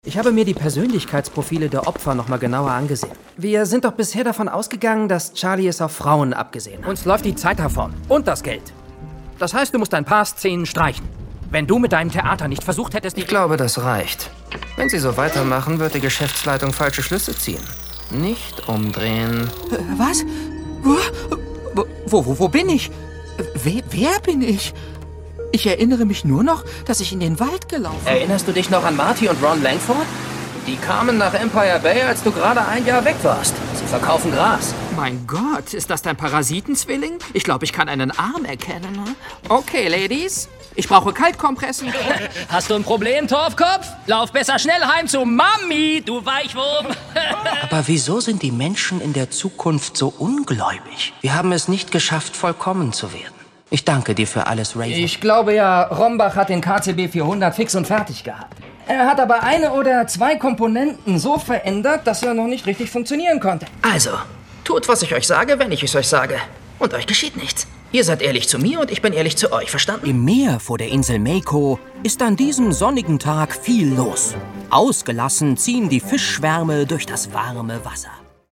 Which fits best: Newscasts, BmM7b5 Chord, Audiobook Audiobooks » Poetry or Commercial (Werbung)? Commercial (Werbung)